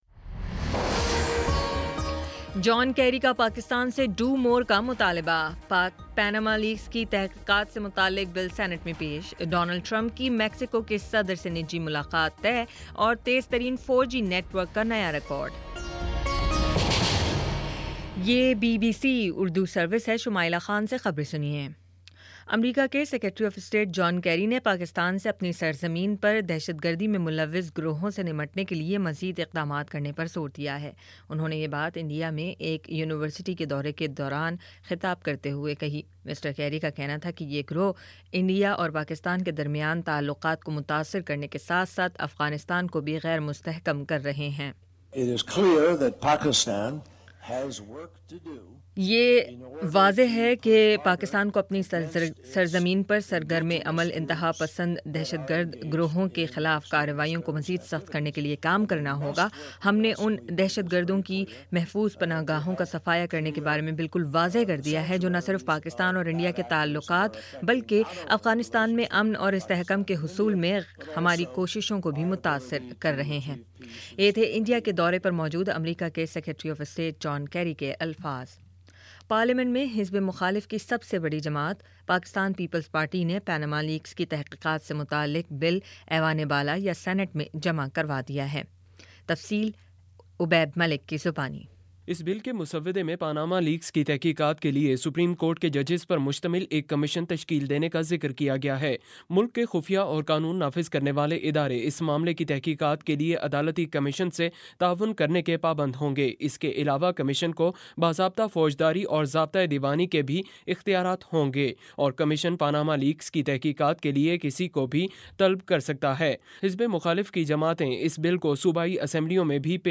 اگست 31 : شام چھ بجے کا نیوز بُلیٹن